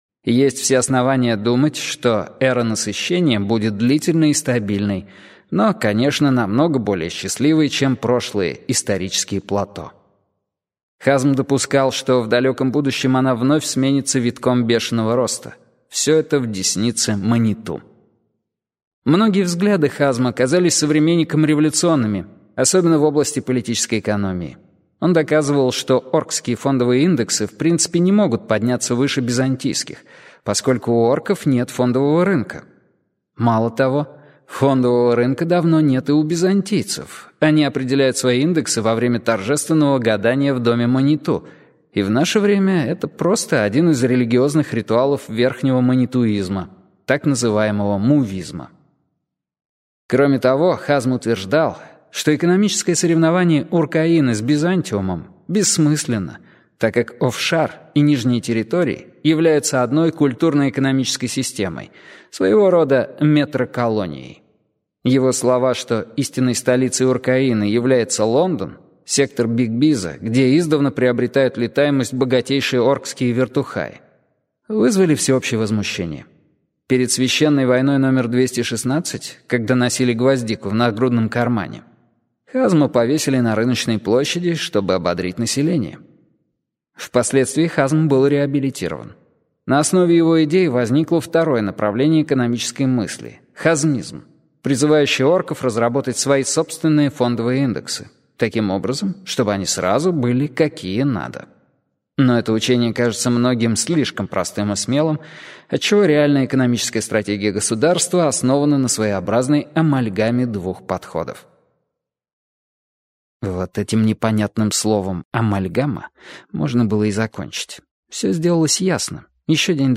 Аудиокнига S.N.U.F.F. | Библиотека аудиокниг
Aудиокнига S.N.U.F.F. Автор Виктор Пелевин Читает аудиокнигу